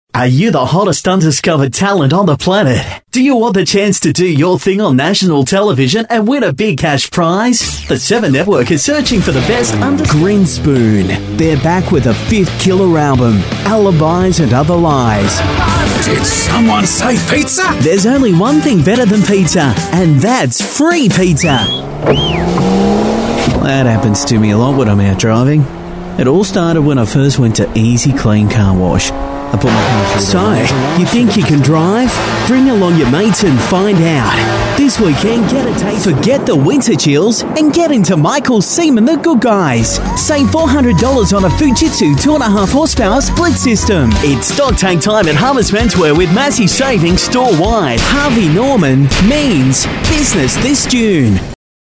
Professionelle Sprecher und Sprecherinnen
Englisch (AUS)
Männlich